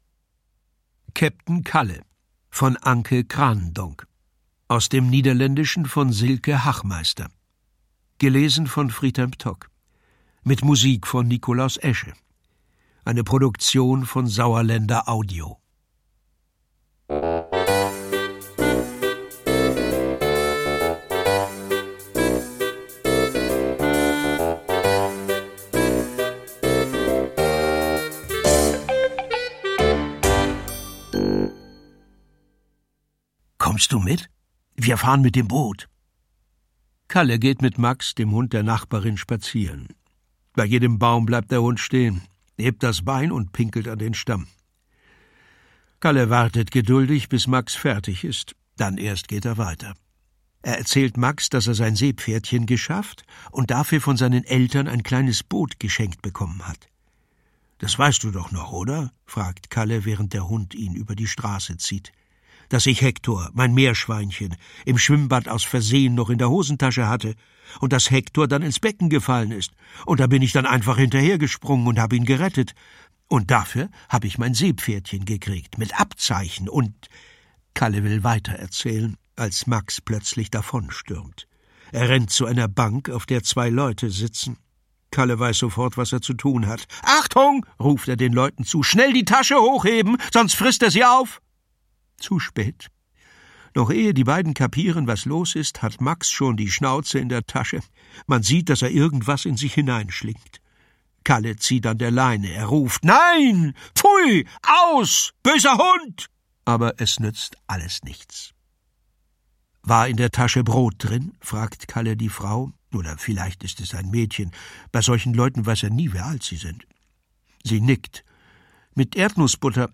Schlagworte Abenteuer • Boot • Demenz • Familie • Fluss • Flussfahrt • Freundschaft • Holland • Hörbuch; Lesung für Kinder/Jugendliche • Hund • Junge • Kanal • Kapitän • Kinder/Jugendliche: Gegenwartsliteratur • Kinder/Jugendliche: Lustige Romane • Kinder/Jugendliche: Natur- & Tiergeschichten • Kinder/Jugendliche: Natur- & Tiergeschichten • Meerschweinchen • Niederlande • Schiff • Seefahrt • Seepferdchen • Selbstbewusstsein • Selbstständigkeit • Vorschule und Kindergarten • Wasser • Weglaufen